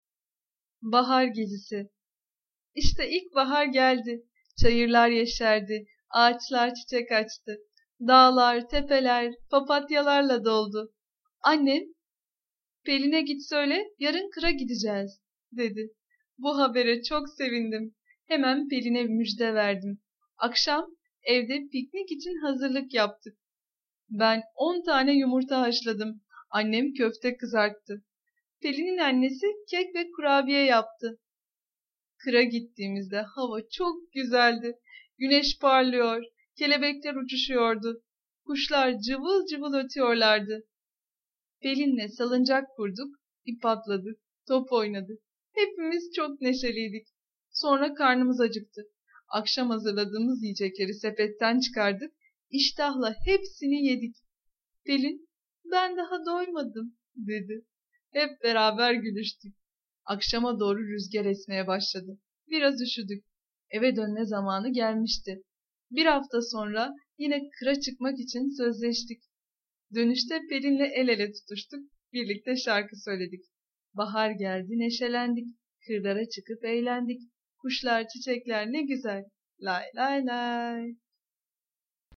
Bahar Gezisi sesli masalı, mp3 dinle indir
Sesli Çocuk Masalları